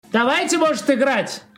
Мемный звук от популярного блогера Мазелова «Ура Роблокс».